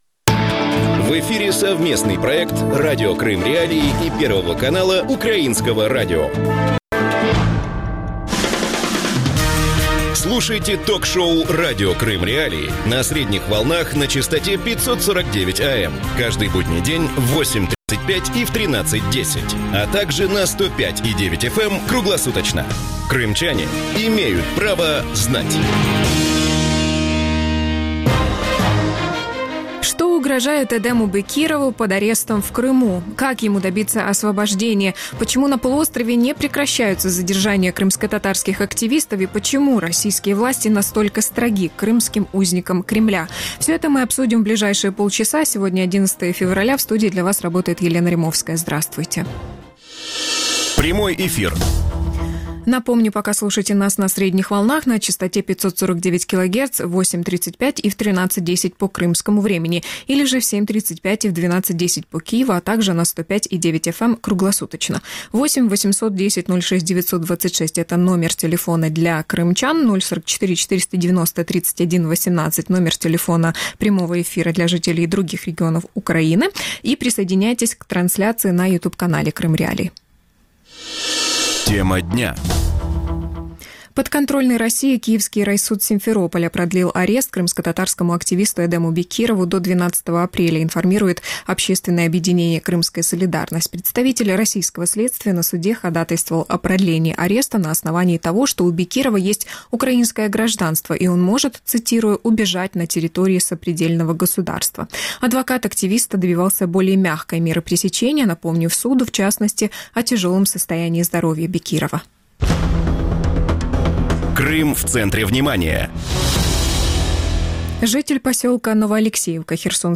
Почему на полуострове не прекращаются задержания крымскотатарских активистов? Гости эфира
Александра Матвийчук, украинская правозащитница, руководитель Центра общественных свобод